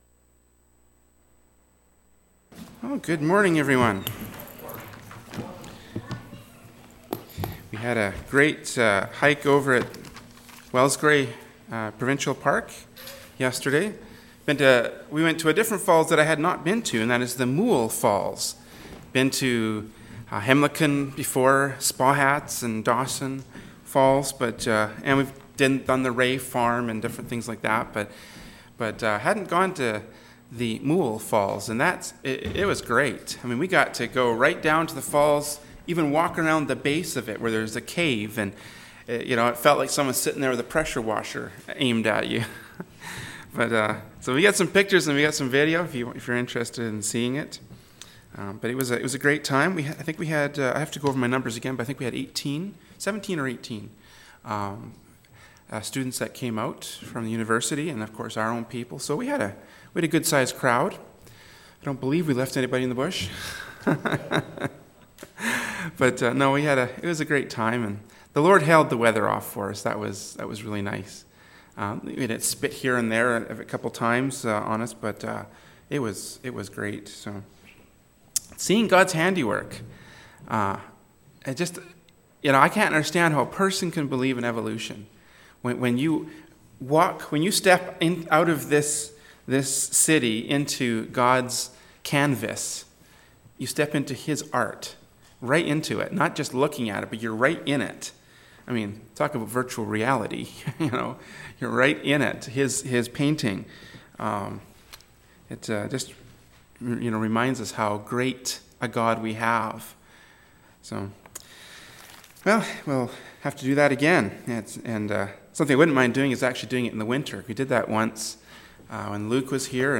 “Proverbs 18:10-16” from Sunday School Service by Berean Baptist Church.